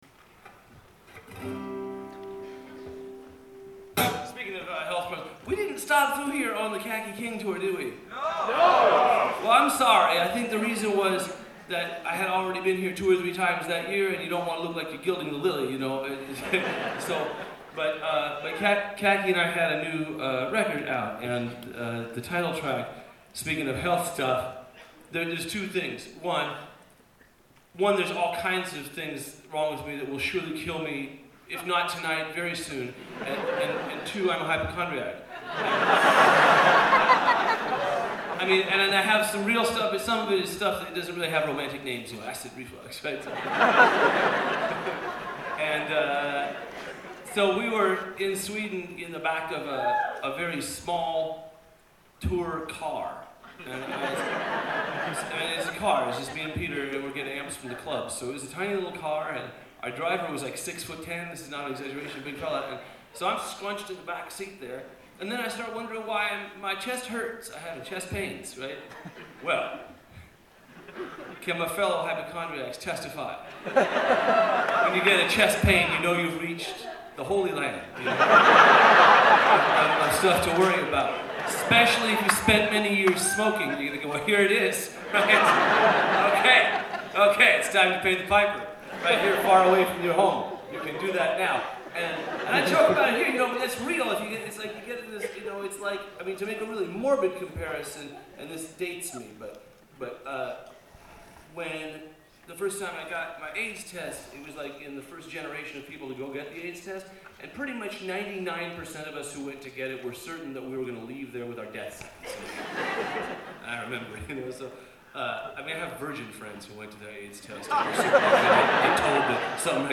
Live at the Somerville Theatre
banter
09-MountainGoats-banter(live-solo).mp3